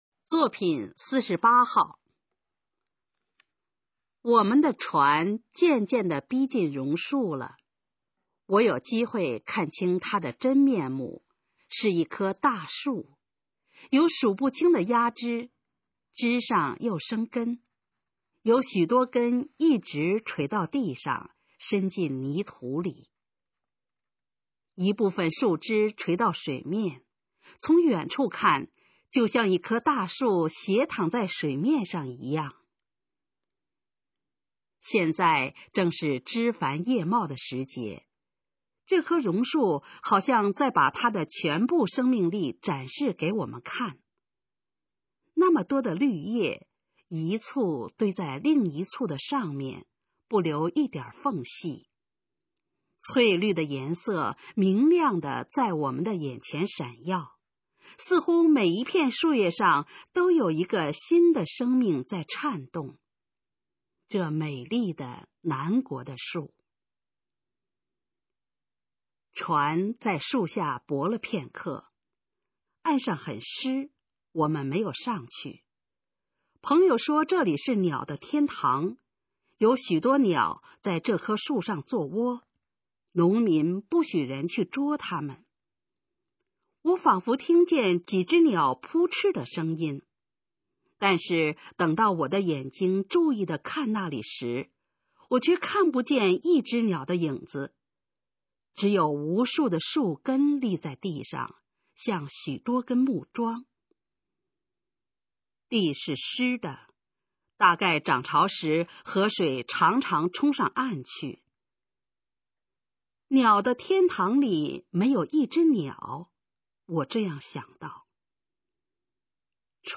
《鸟的天堂》示范朗读_水平测试（等级考试）用60篇朗读作品范读